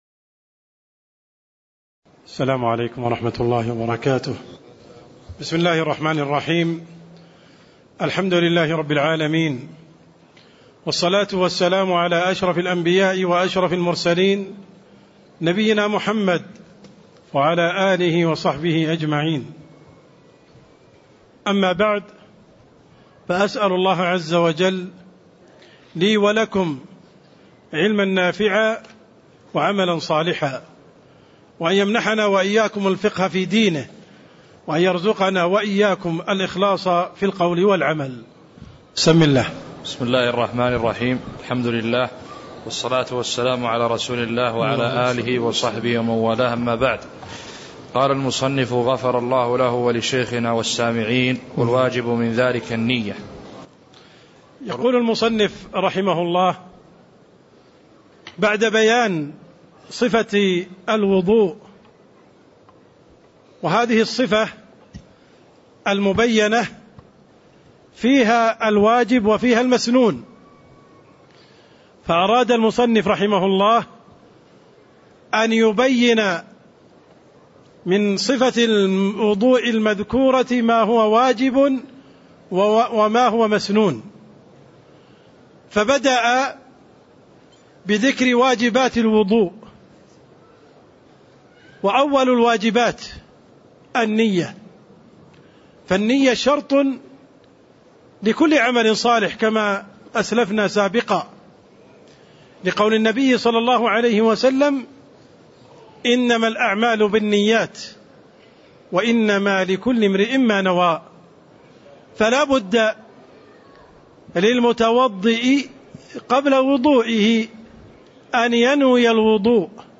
تاريخ النشر ١ جمادى الآخرة ١٤٣٥ هـ المكان: المسجد النبوي الشيخ: عبدالرحمن السند عبدالرحمن السند باب الوضوء (07)